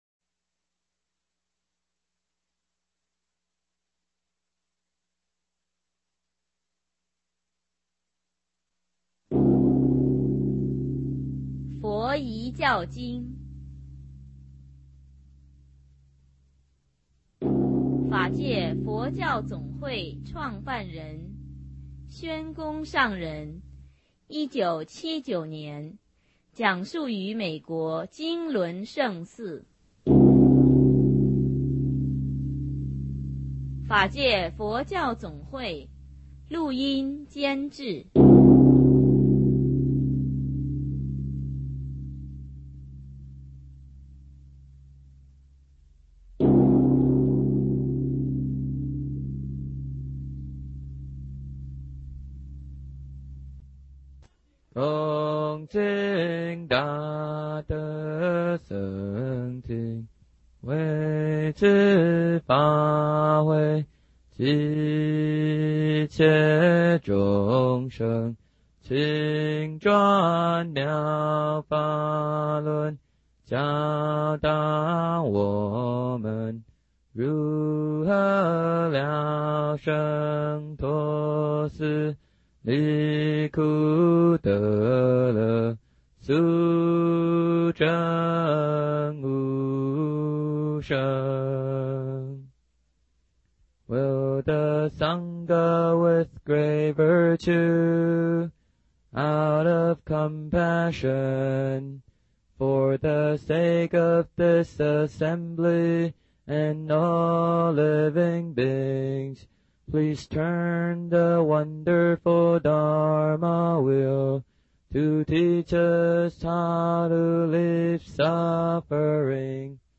XH_FYJJQS01.mp3 檔案下載 - 佛學多媒體資料庫 佛學多媒體資料庫 > 佛學講座 > 佛學講座-聲音檔 > 宣化上人 > 佛遺教經淺釋 > XH_FYJJQS01.mp3 > 檔案下載 Download 下載: XH_FYJJQS01.mp3 ※MD5 檢查碼: 12D9F72566A02DC613A9C9B6BABE0DF6 (可用 WinMD5_v2.exe 檢查下載後檔案是否與原檔案相同)